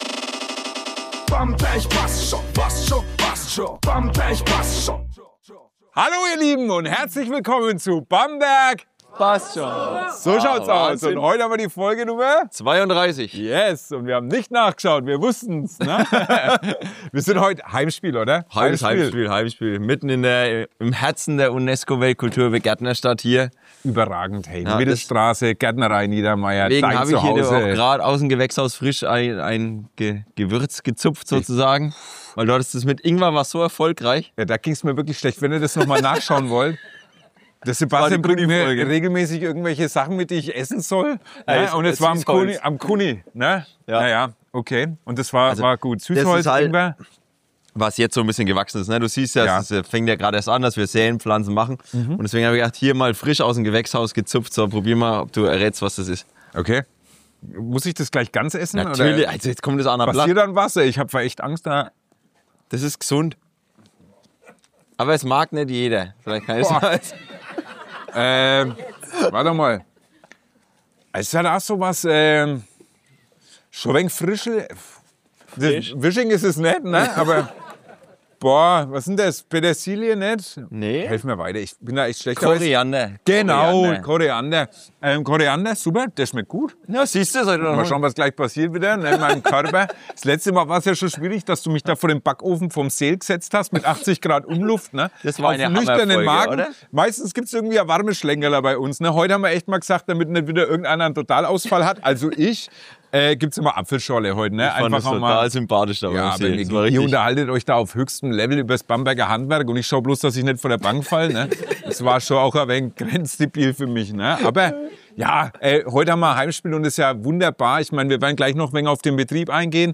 Mitten im Gewächshaus sprechen wir über echtes Handwerk, Familienbetrieb und den Weg vom Nebenerwerb zur deutschlandweit bekannten Bio-Gärtnerei. Natürlich geht’s auch um die OB-Wahl, Emotionen pur zwischen erstem Wahlgang und Stichwahl – näher dran geht nicht. Dazu: Koriander-Test, Gewächshaus-Storys, Sterneküche zwischen Tomaten und ganz viel Bamberg-Gefühl.